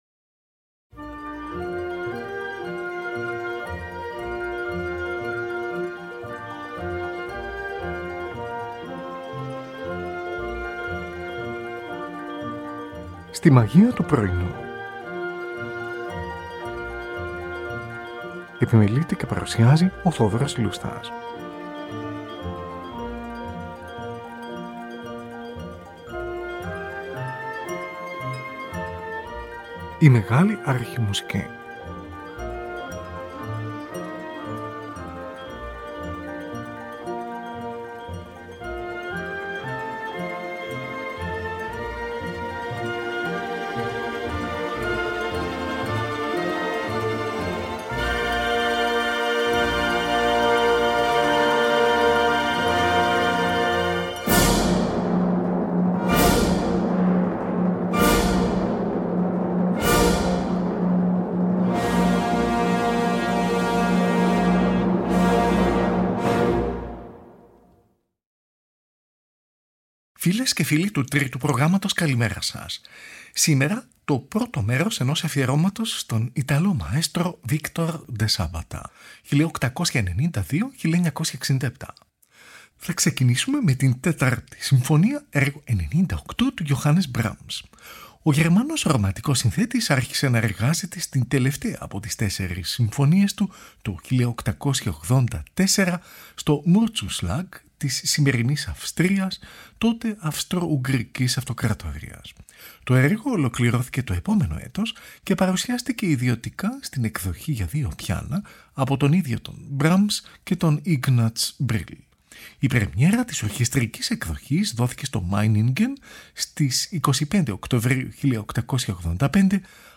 Ορχηστρικό Πρελούδιο